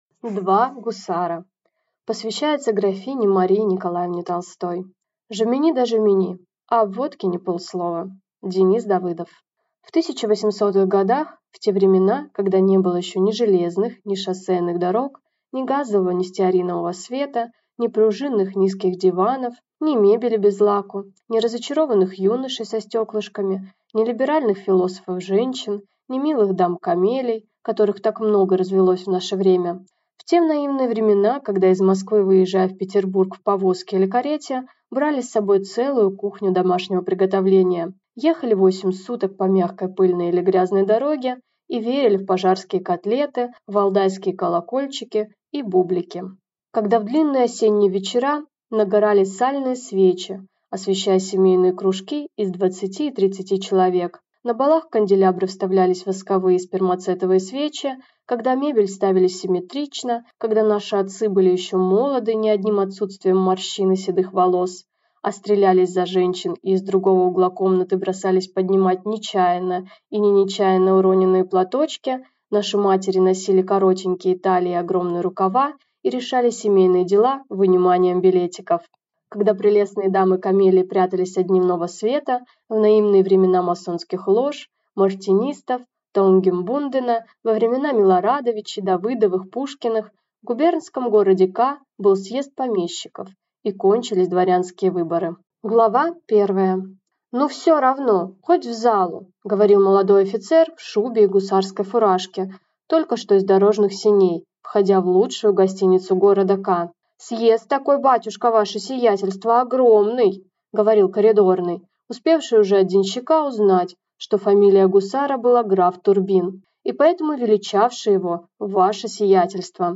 Aудиокнига Два гусара